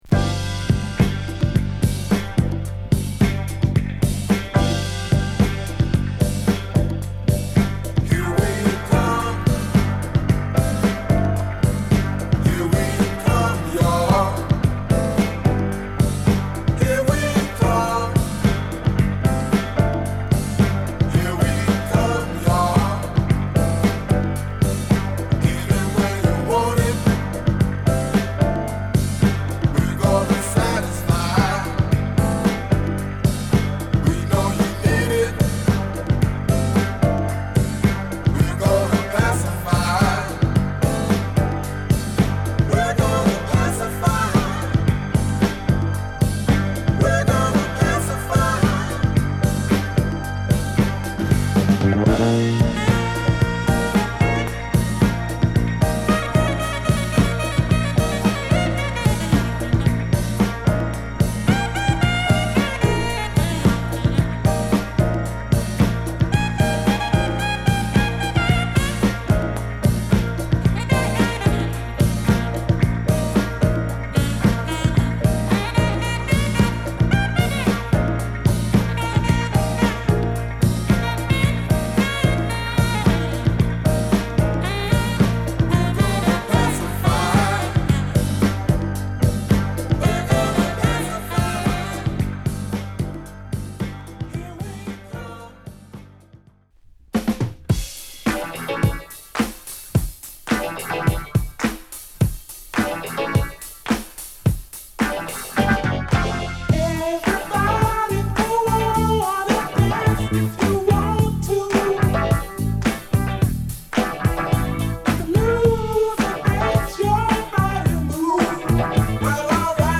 アトランタ発のファンクバンド
軽快なビートに印象的なシンセリフが絡むファンクチューン！